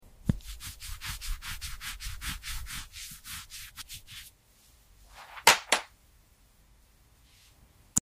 The sound of the stone being cleaned and polished into a beautiful blue gem is so calming.